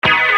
Hit 010.wav